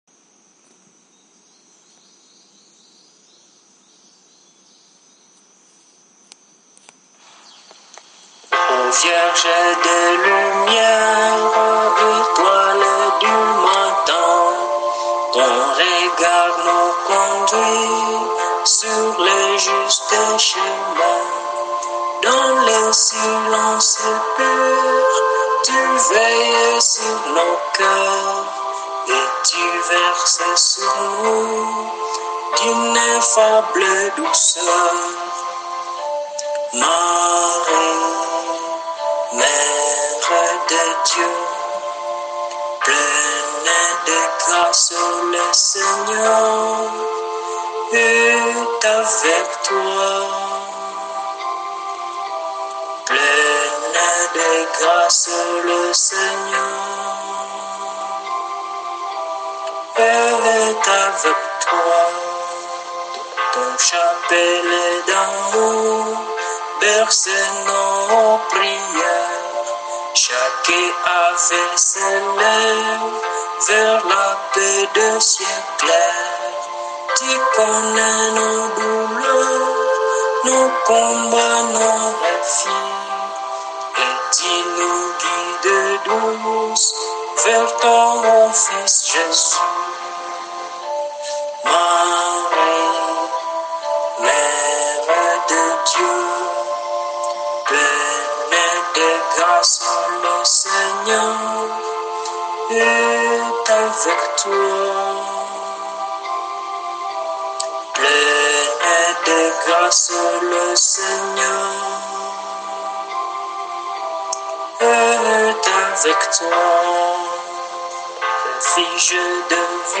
• Catégorie : Chants de Sainte Vierge Marie